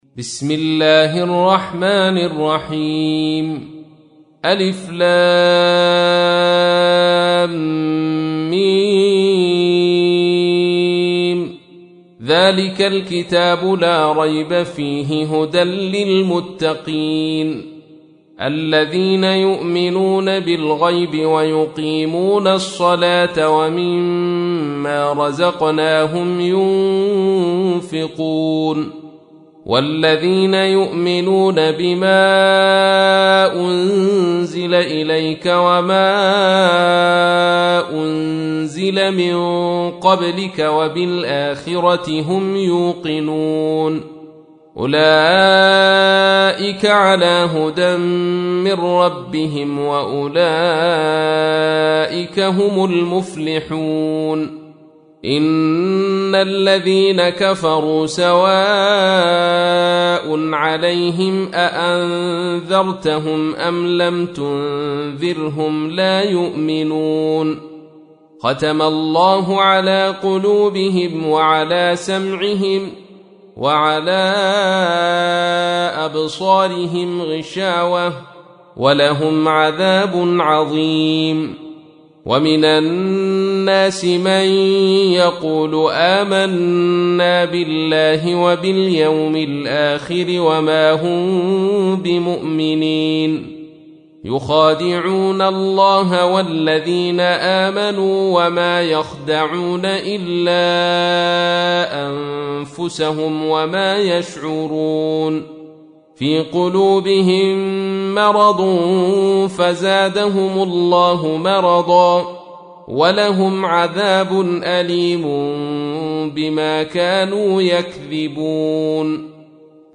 تحميل : 2. سورة البقرة / القارئ عبد الرشيد صوفي / القرآن الكريم / موقع يا حسين